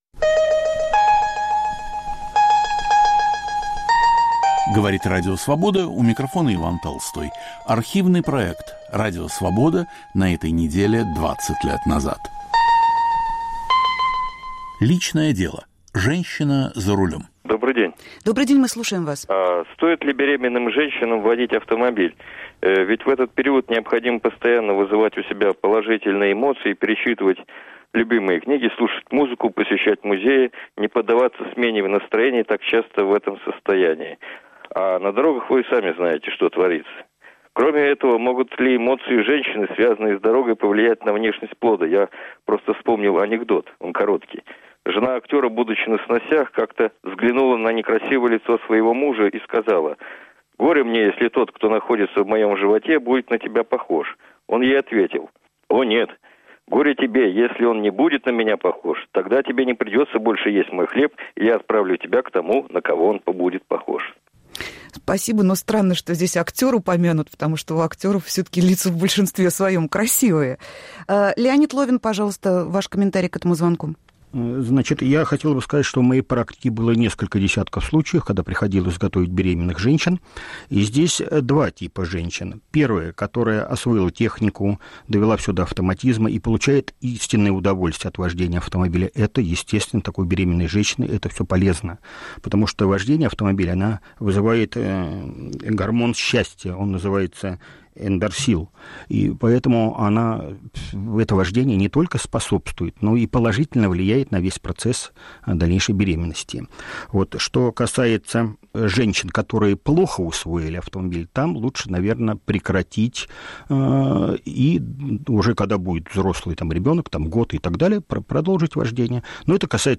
Когда вождение машины доставляет женщине удовольствие. В передаче участвуют политтехнолог